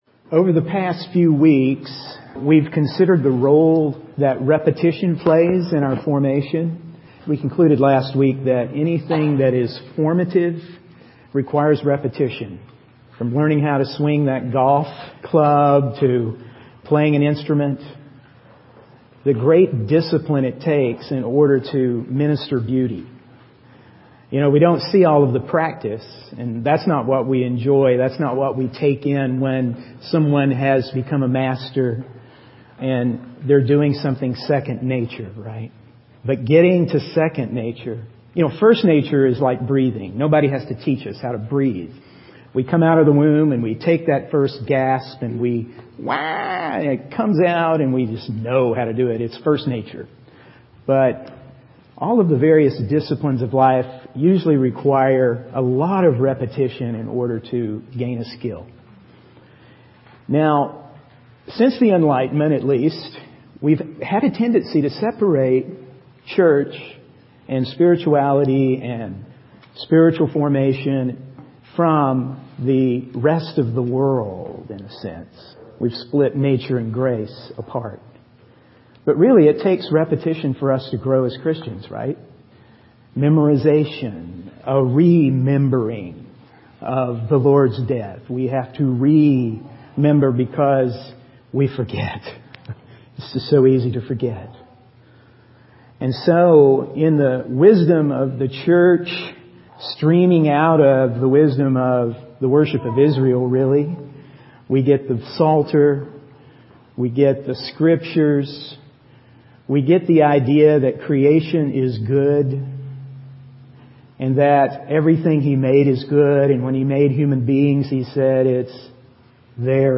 In this sermon, the speaker emphasizes the importance of repetition in Christian growth and formation. They highlight the idea that sin has caused a break in communion between humanity and God. The speaker also discusses the need for intentional and liturgical worship, where believers offer their bodies and minds as living sacrifices.